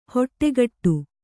♪ hoṭṭegaṭṭu